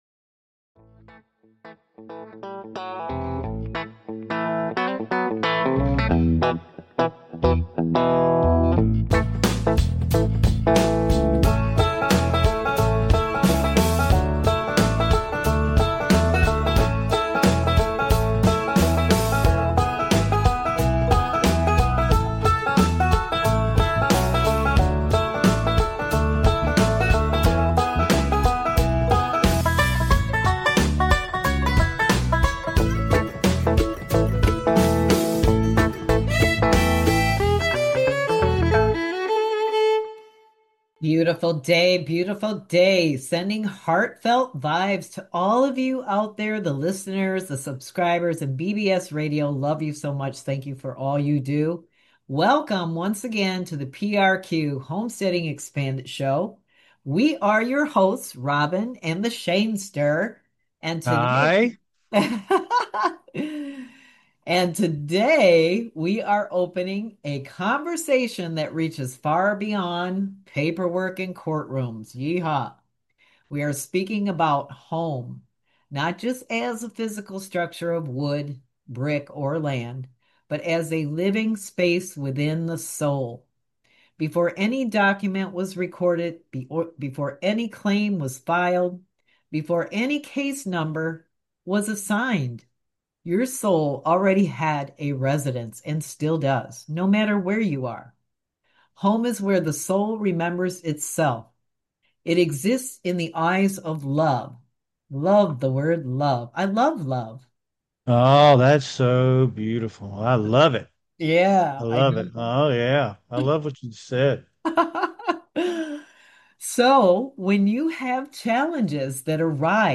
Talk Show Episode, Audio Podcast, PRQ Homesteading Expanded and Homestead 1099 Prepper Debt Strategies on , show guests , about Homestead 1099,Prepper Debt Strategies,spiritual sanctuary,love frequency,bankruptcy,commercial law,debt discharge strategies, categorized as Business,Health & Lifestyle,Kids & Family,Philosophy,Politics & Government,Local,Psychology,Self Help,Society and Culture